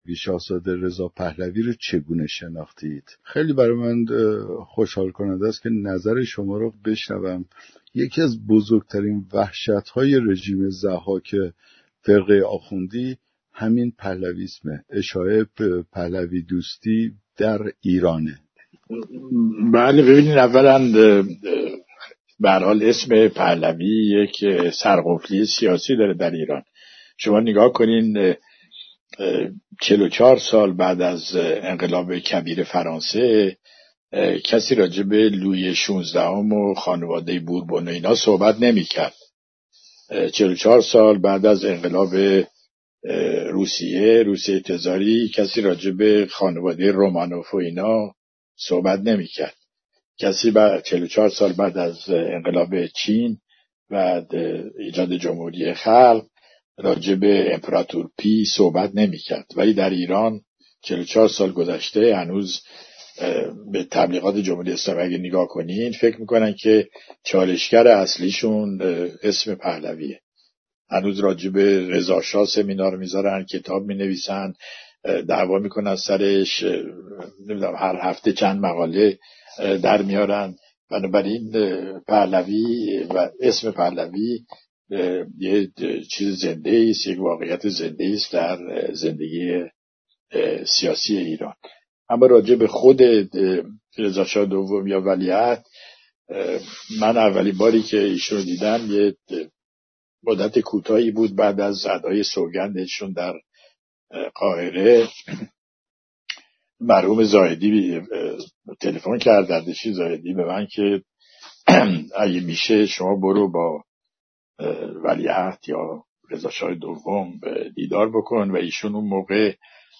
بیانات استاد در باره رضاشاه پهلوی دوم در کلاب هاوس: